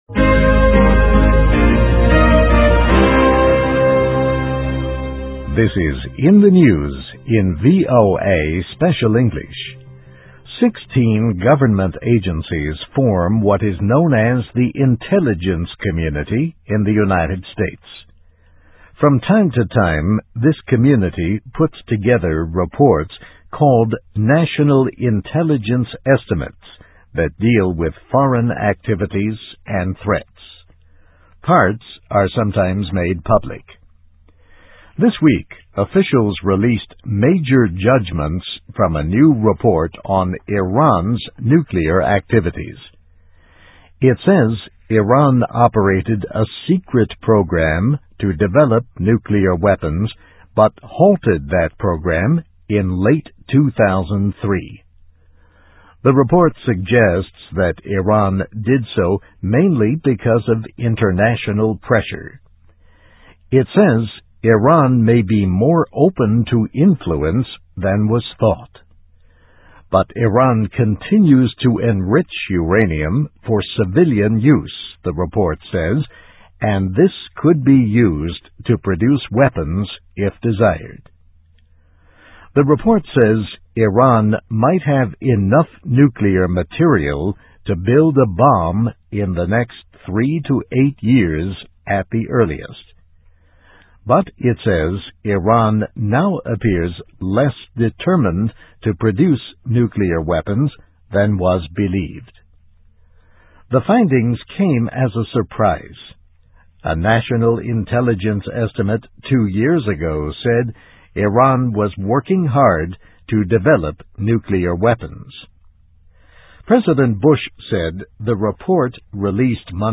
US Intelligence Report Enters Into Debate on Iran Nuclear Issue (VOA Special English 2007-12-07)